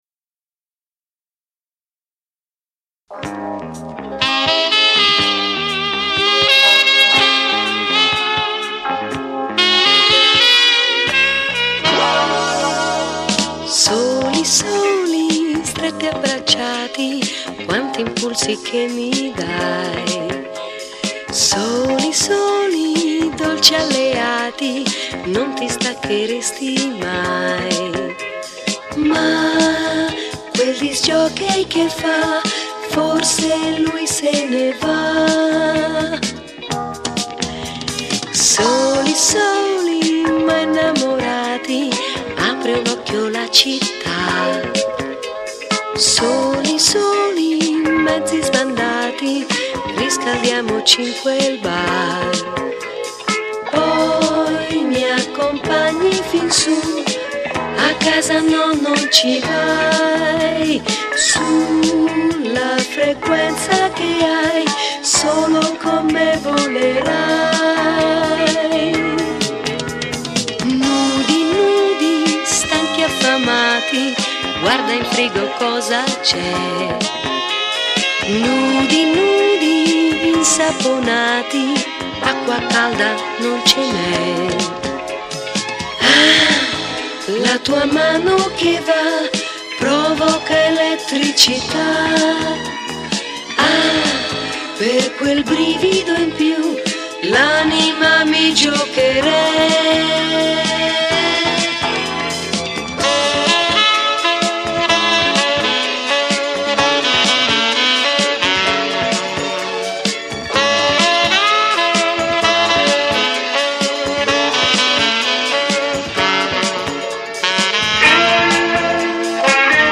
RECORDED & REMIXED AT BUS STUDIOS - ROME